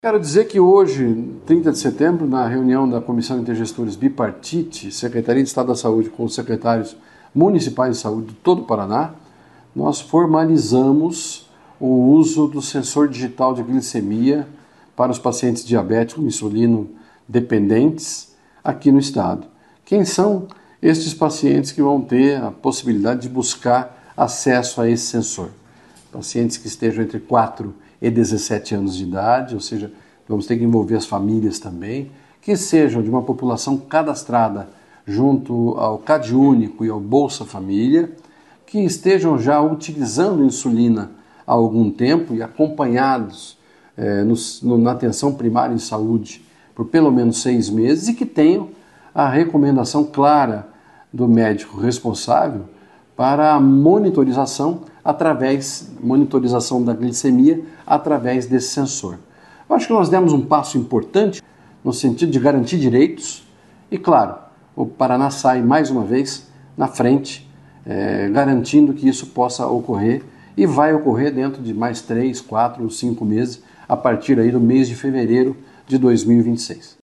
Sonora do secretário da Saúde, Beto Preto, sobre o Paraná ser o primeiro estado a formalizar distribuição gratuita de sensores digitais de glicemia